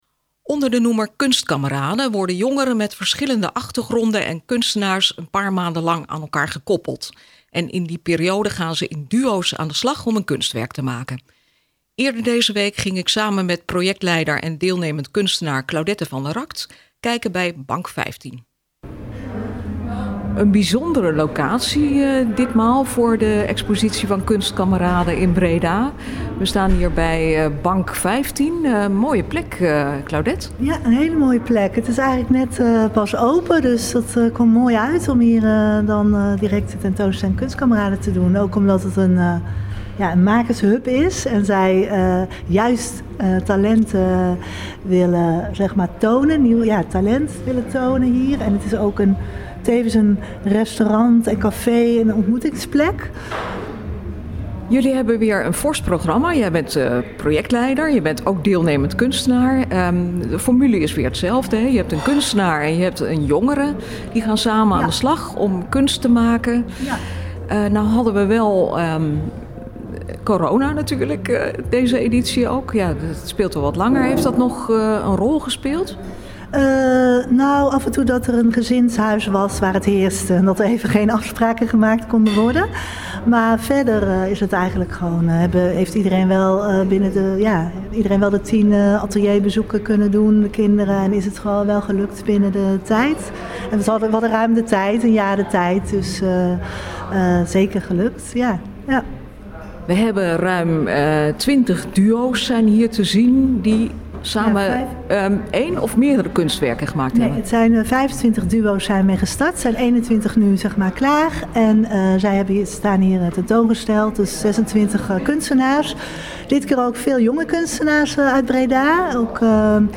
op de expositielocatie van BANK15 Breda